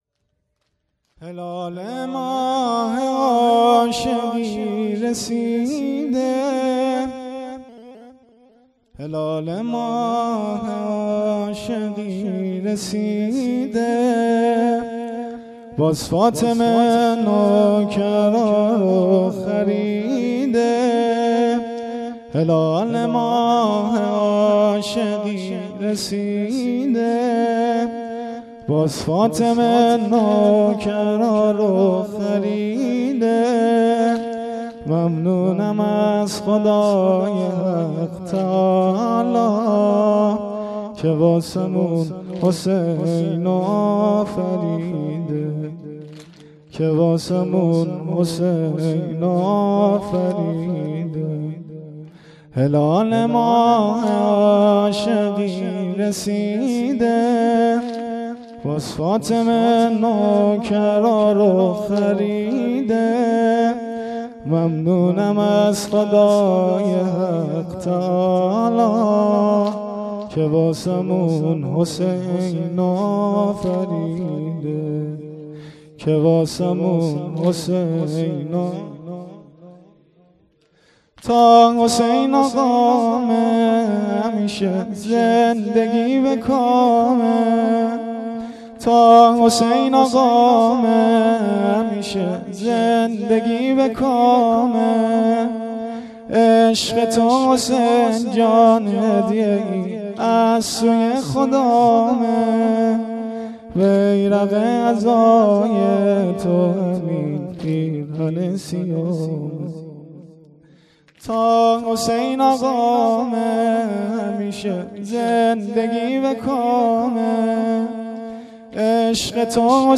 مراسم عزاداری محرم ۱۴۰۴